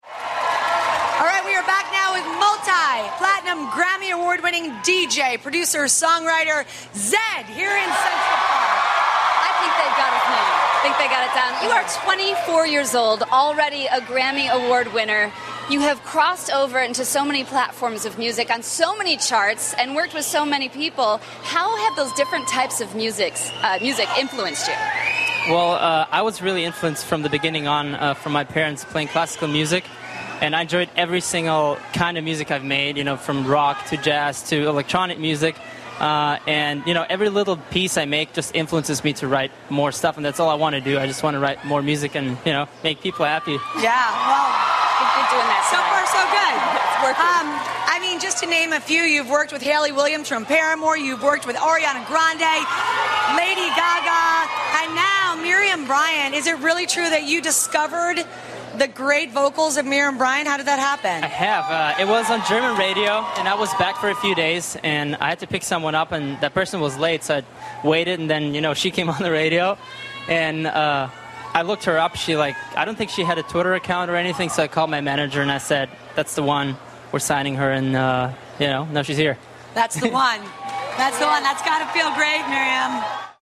访谈录 2014-07-25&07-27 德国萌DJ Zedd专访 听力文件下载—在线英语听力室